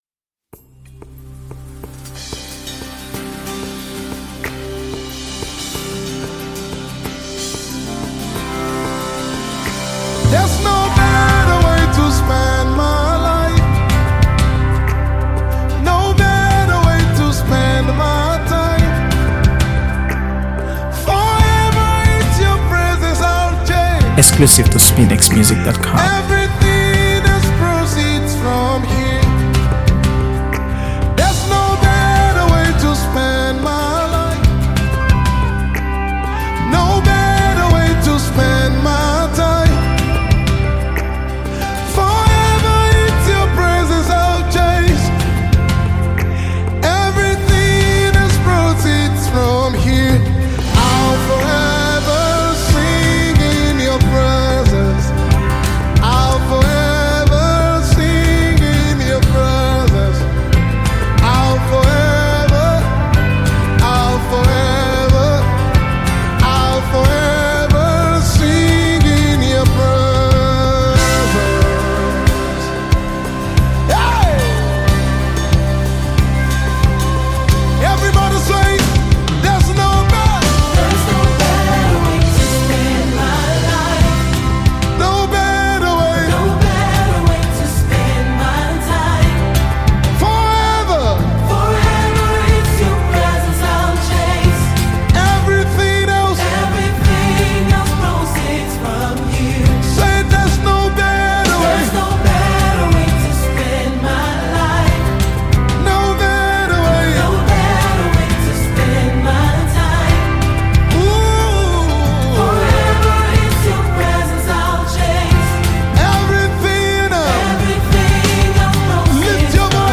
spirit-lifting worship song